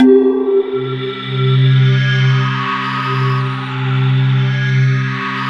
Index of /90_sSampleCDs/USB Soundscan vol.13 - Ethereal Atmosphere [AKAI] 1CD/Partition D/04-ACTUALSYN